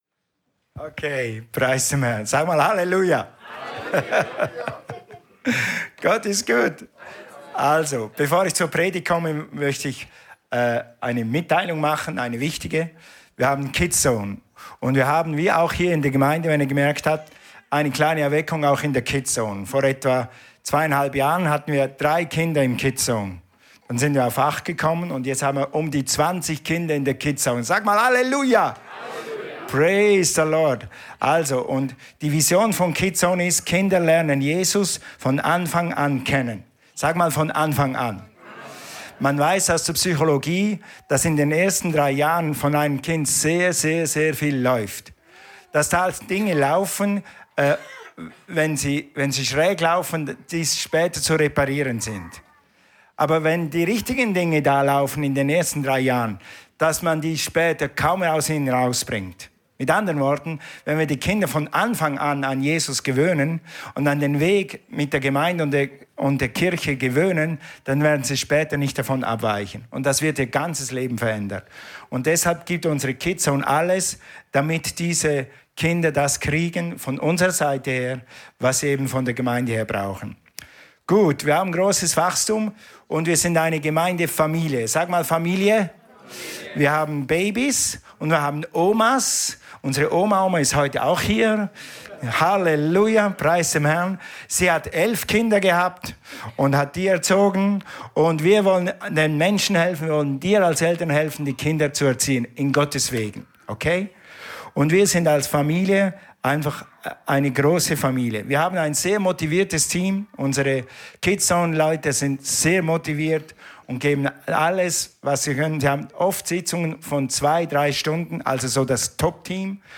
Alle Predigten aus den Sonntagsgottesdiensten